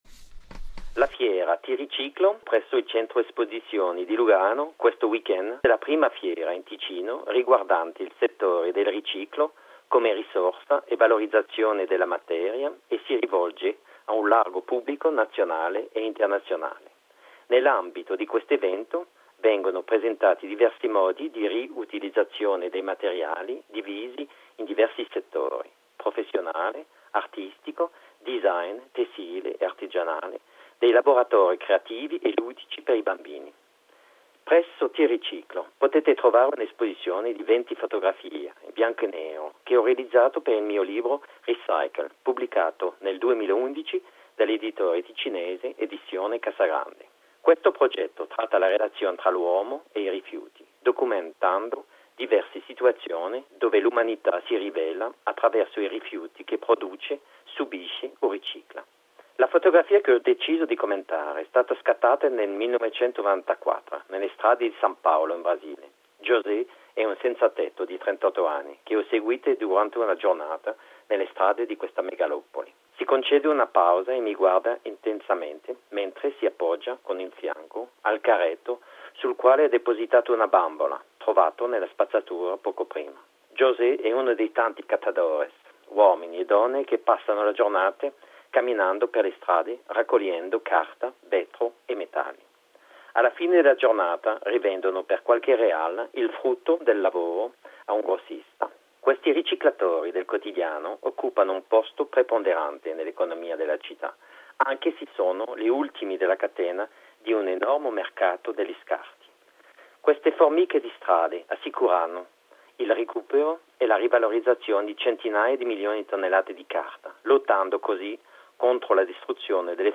A radio comment about the image “Paper recycling in Brazil”.